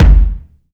SouthSide Kick Edited (44).wav